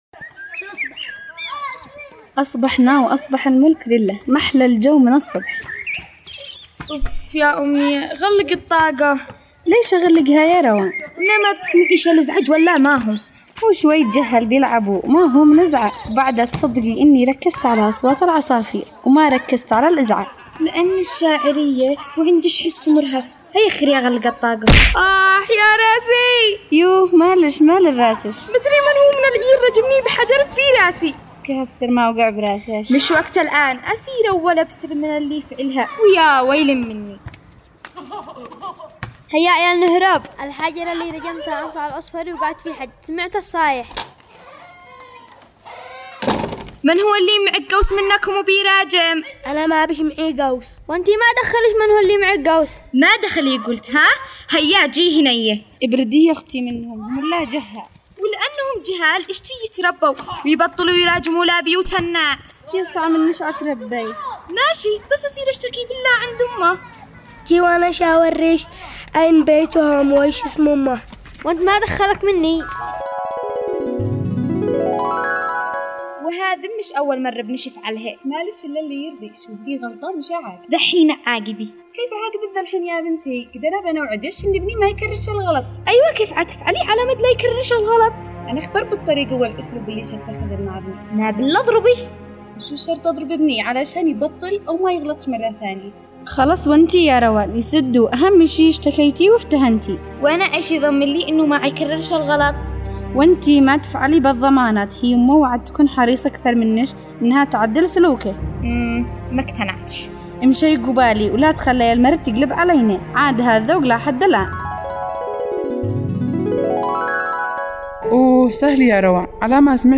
دراما رمضانية